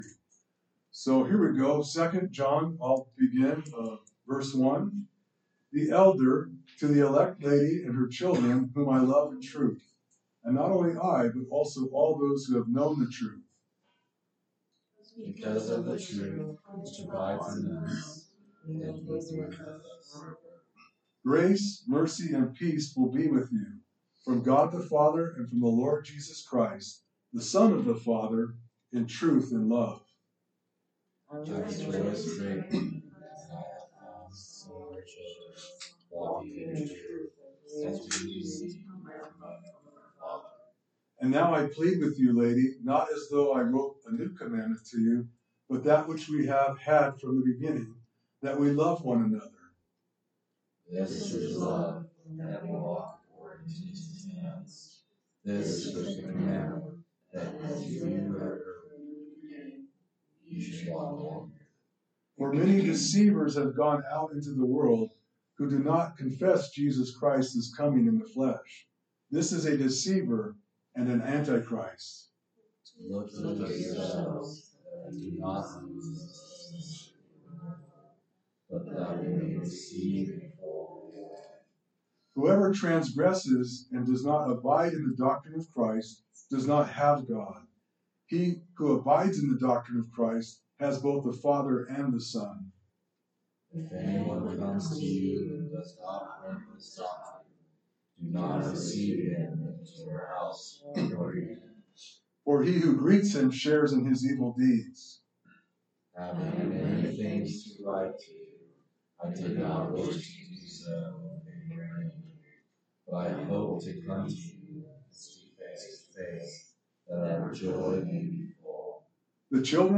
Sermons - Calvary Chapel Ames